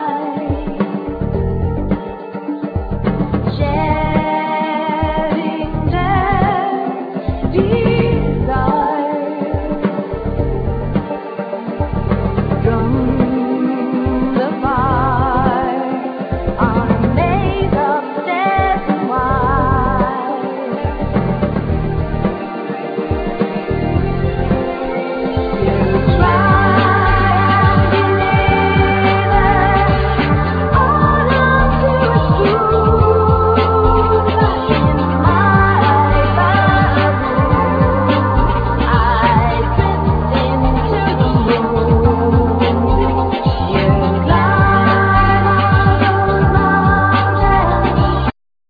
Vocals,Mandolin,Ac.guitar
Piano
Flute
Cello
String Quartet
Barabuka,Drums,Percussions,Classical guitar
Keyboards,Sound effects
Tiple,Charango,Glissando,Angel Harp